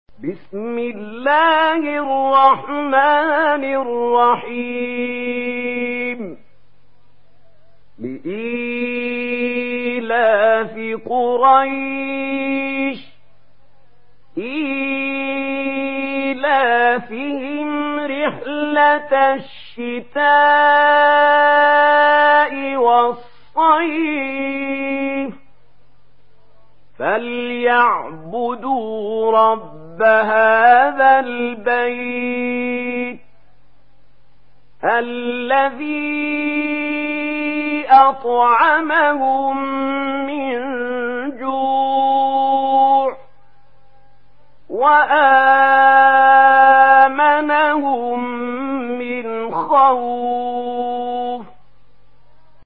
سورة قريش MP3 بصوت محمود خليل الحصري برواية ورش
مرتل ورش عن نافع